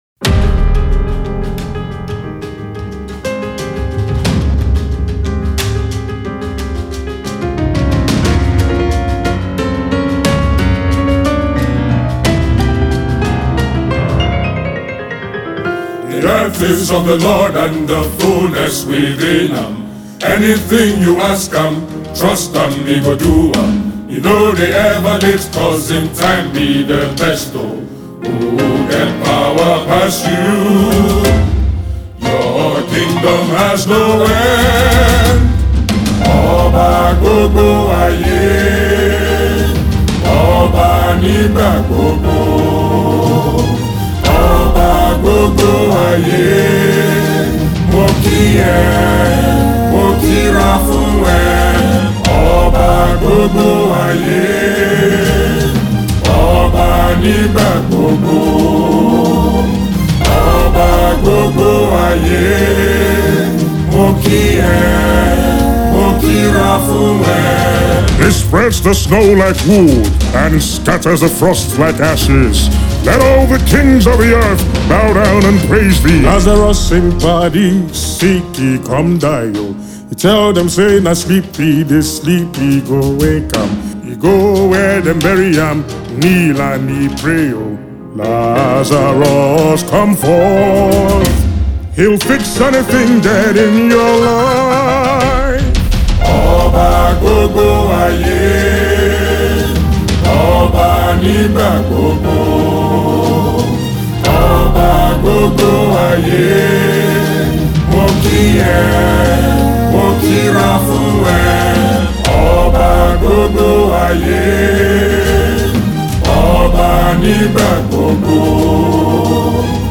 a worship ballad